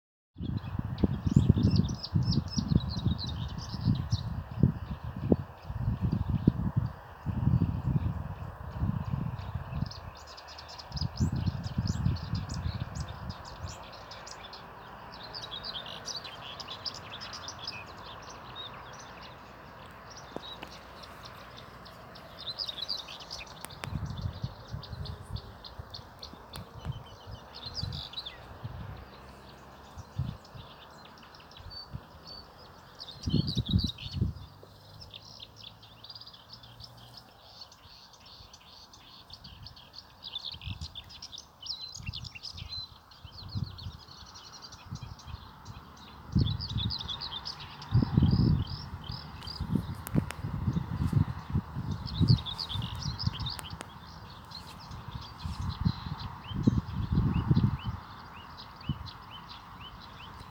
Osluhnemo li malo bolje, otkrit ćemo da uz zvuk automobila i šum vjetra u pozadini, možemo čuti predivan
cvrkut ptica.
cvrkut_ptica.m4a